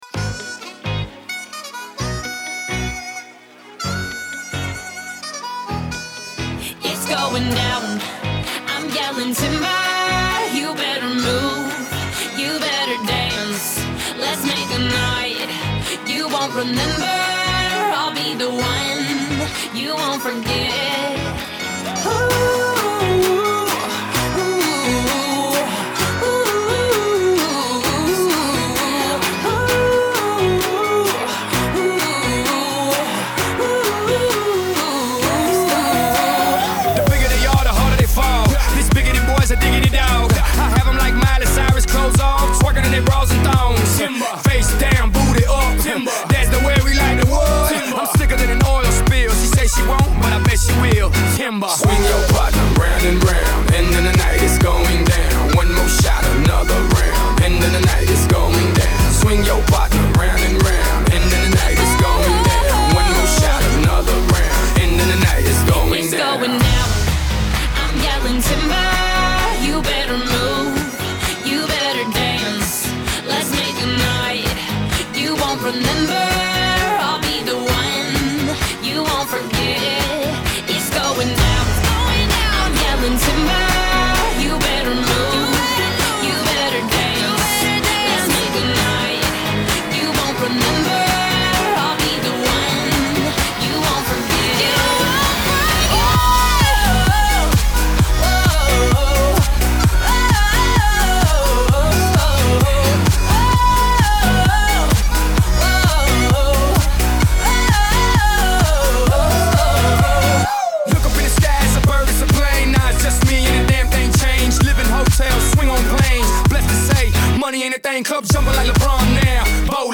Pop 2010er